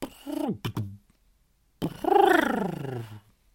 描述：Beatbox声音/循环2bars 135bpm 付出就有回报功不可没。
Tag: 创意 敢-19 循环 口技